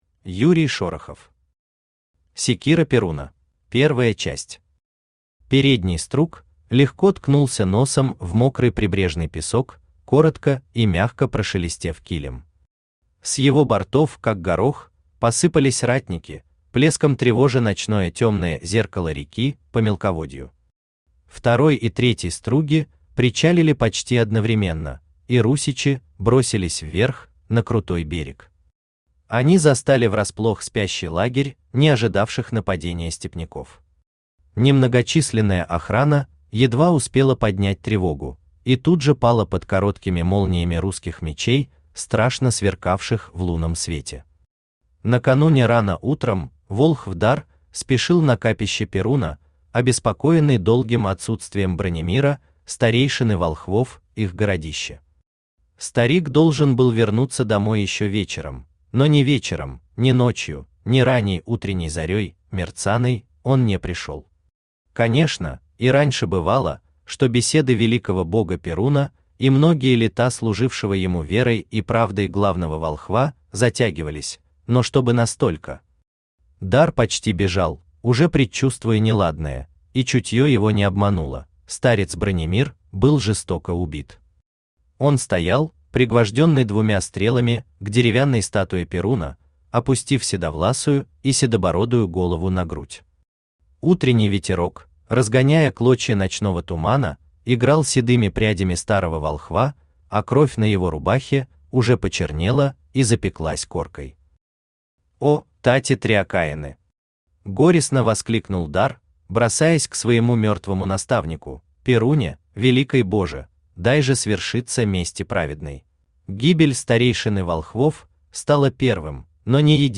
Аудиокнига Секира Перуна | Библиотека аудиокниг